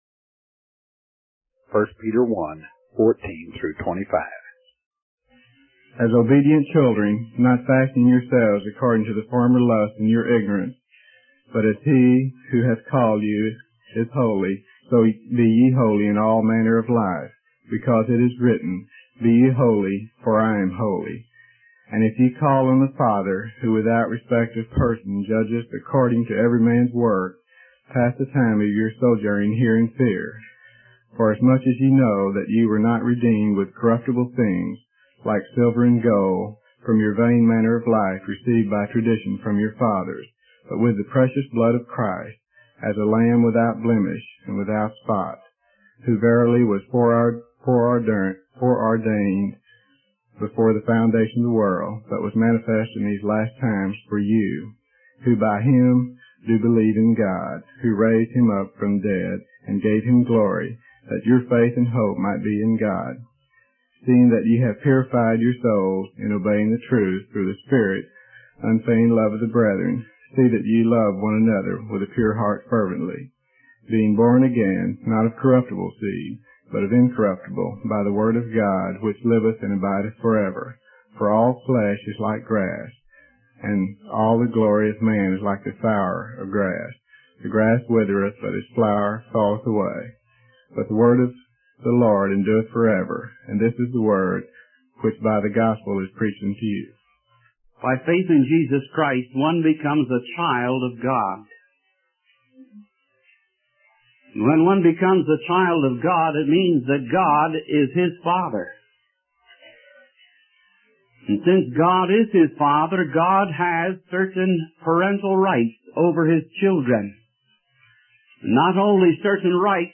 In this sermon, the preacher emphasizes the importance of love among believers. He highlights that love should be genuine, selfless, and extend to the entire body of believers.